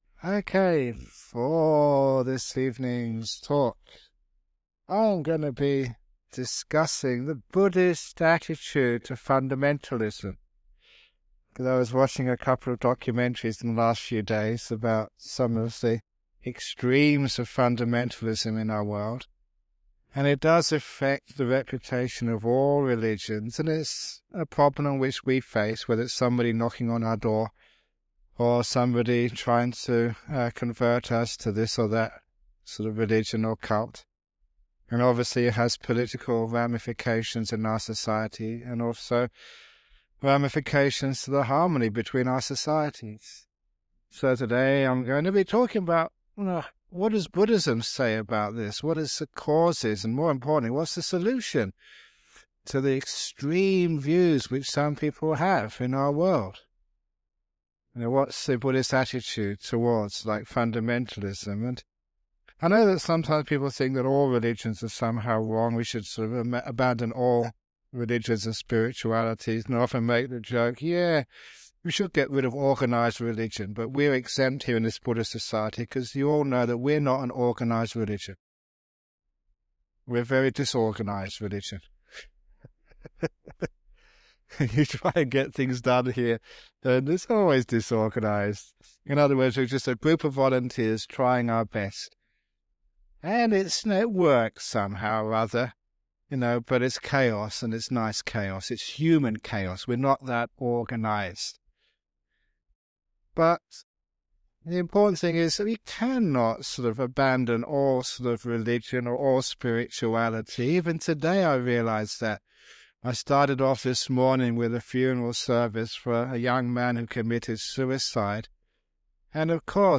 Ajahn Brahm discusses the Buddhist perspective on fundamentalism and its negative effects on religion and society.